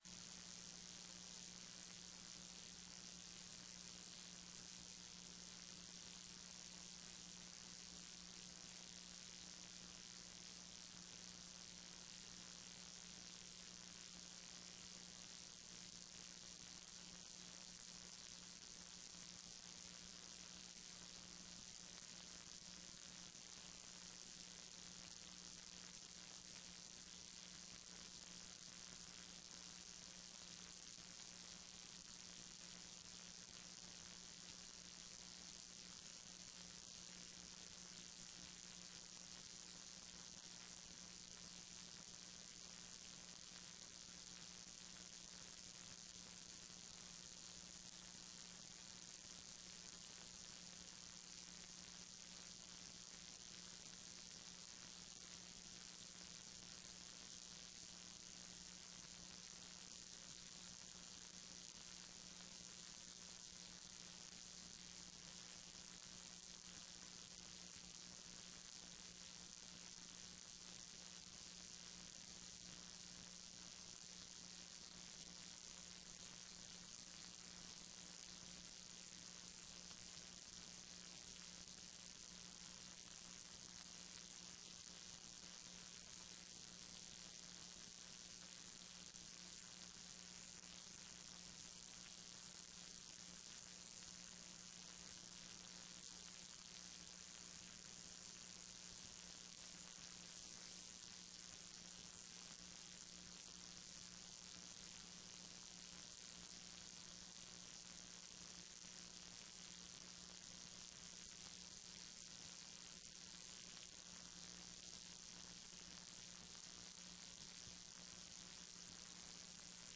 TRE-ES sessao do dia 9 de setembro de 2014